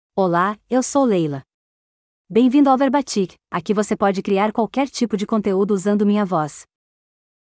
Leila — Female Portuguese (Brazil) AI Voice | TTS, Voice Cloning & Video | Verbatik AI
FemalePortuguese (Brazil)
LeilaFemale Portuguese AI voice
Voice sample
Female
Leila delivers clear pronunciation with authentic Brazil Portuguese intonation, making your content sound professionally produced.